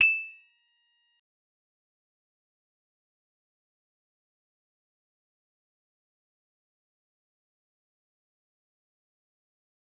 G_Musicbox-E8-mf.wav